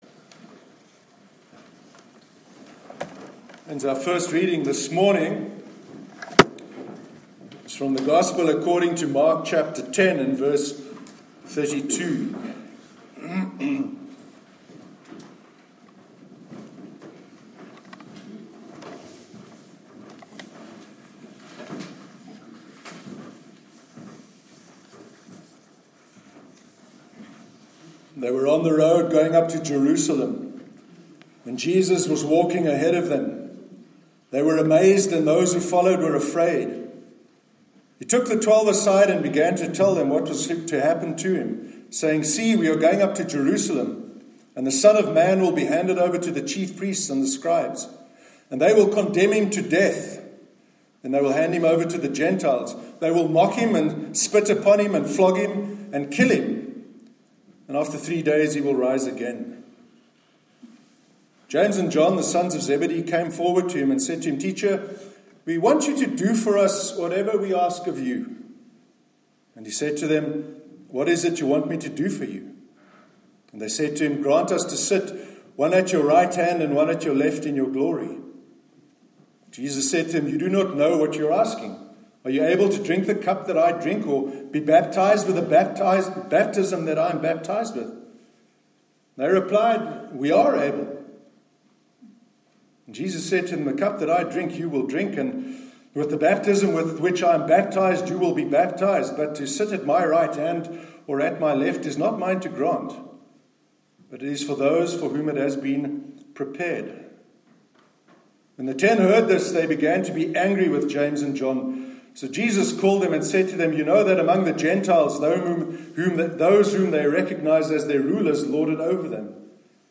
Sermon on Transcending the Culture of Complaint- 25th November 2018